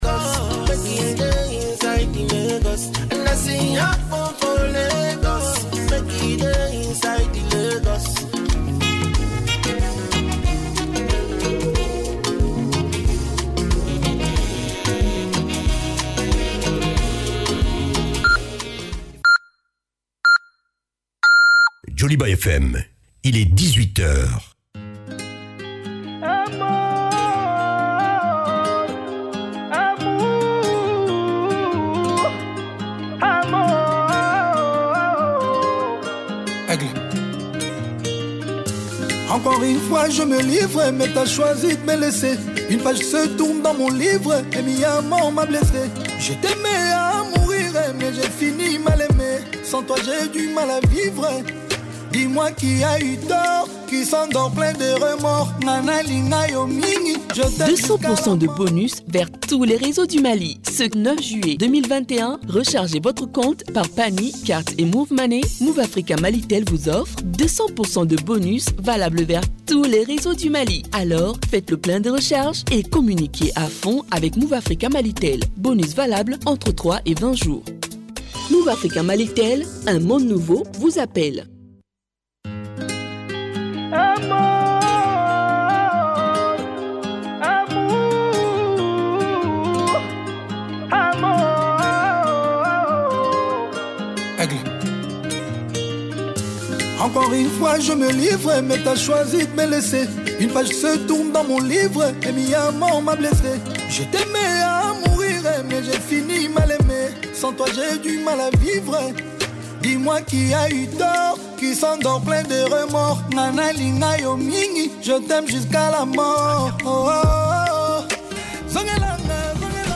Appelle-nous et donne ton point de vue sur une question d’actualité (politique, économique, culturelle, religieuse, etc.). Pas de sujets tabous : arguments, contre arguments !